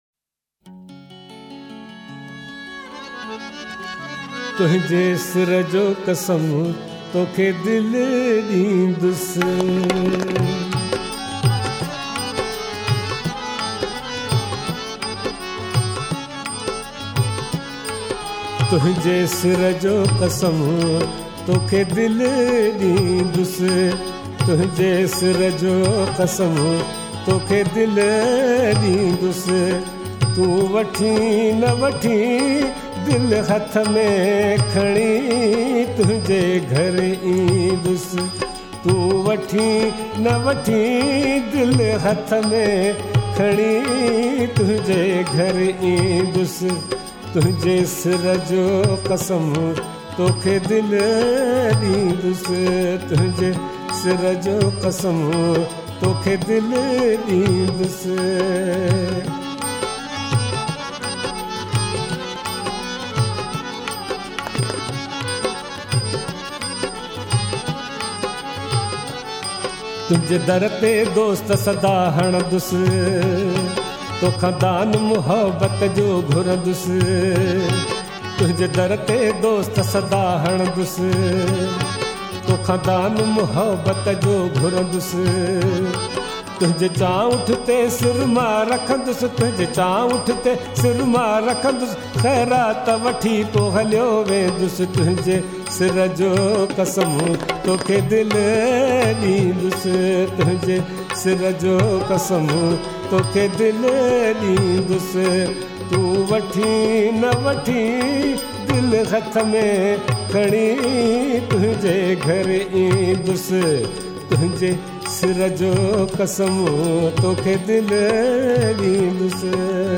in soothing voice